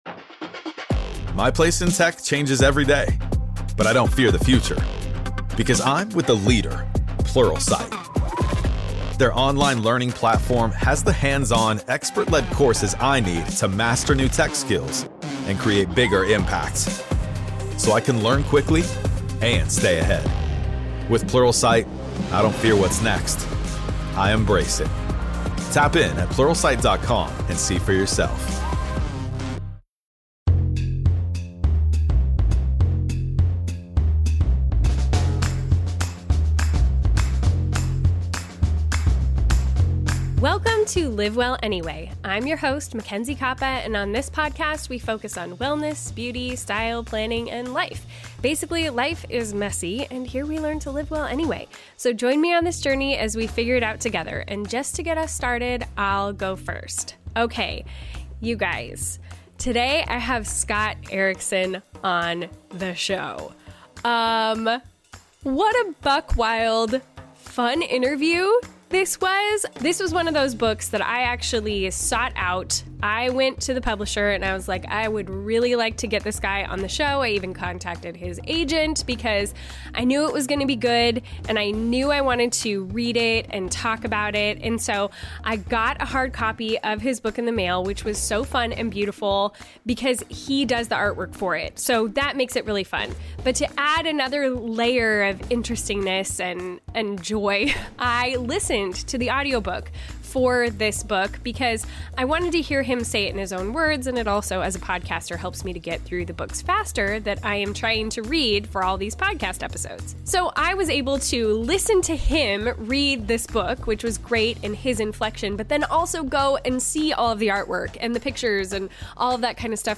(Note of warning for parents: this episode contains some material that is inappropriate for young ears, so listening discretion is recommended.)